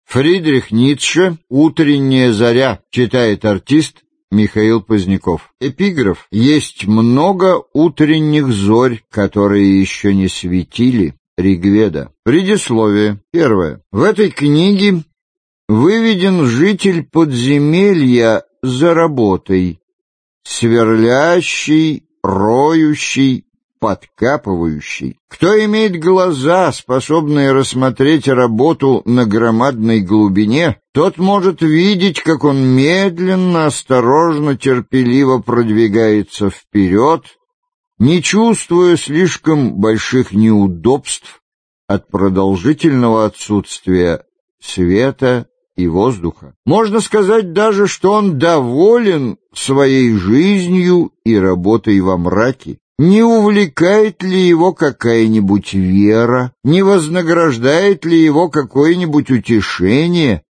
Аудиокнига Утренняя заря | Библиотека аудиокниг